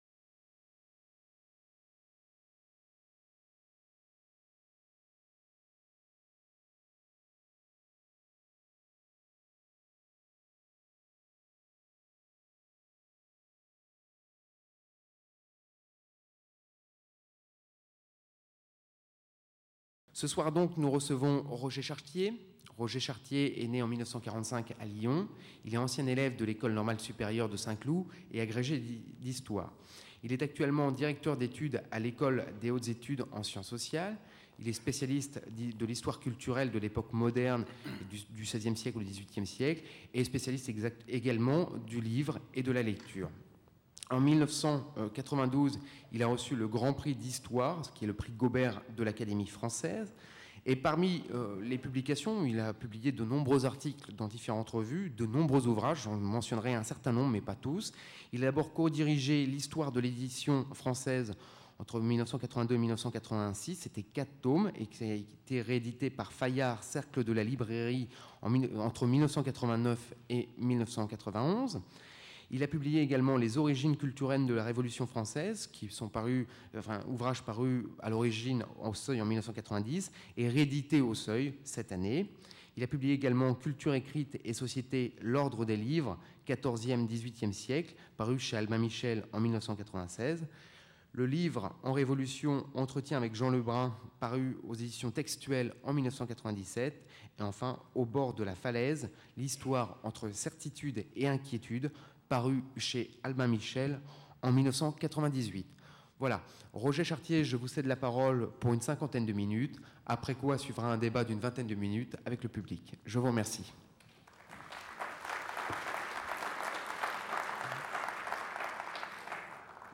Cette conférence voudrait examiner les différentes mutations que la nouvelle textualité électronique propose ou impose aux catégories et pratiques qui ont régi - et régissent encore - notre rapport à la culture écrite. L'exposé s'attachera avant tout à trois ordres de questions.